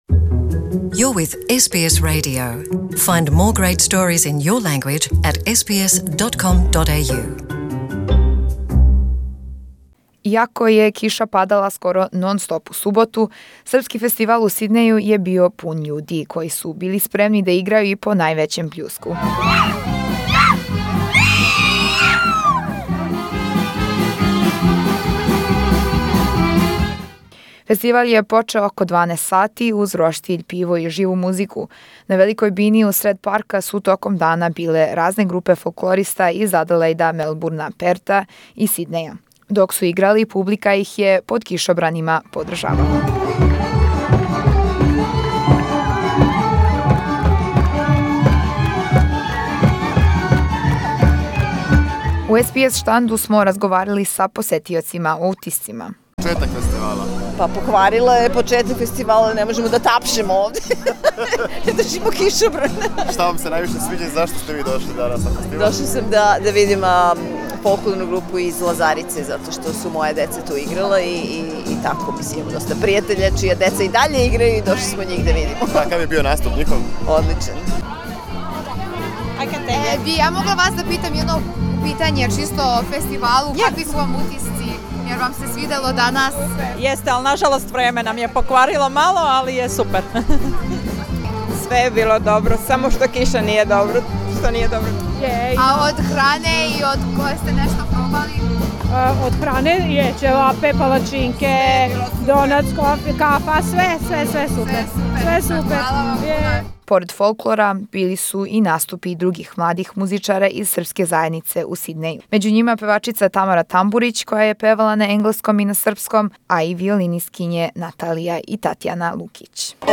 SBS Radio Serbian at the Serbian Festival Sydney 2019 Source: SBS